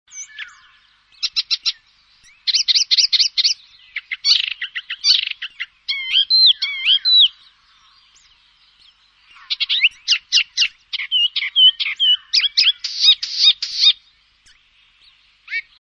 Gelbspötter
Der Gesang des Gelbspötters ist laut und wird von kräftigen Kopfbewegungen begleitet. Mit ,,tschak-tschak"-Rufen imitiert der Gelbspötter auch andere Vögel. Gelbspötter bewohnen sonnige Laubwaldränder, Parkland, Gärten und Kulturland am Rande von Binnengewässern.
gelbspoetter.mp3